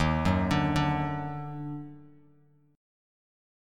D#mM7 chord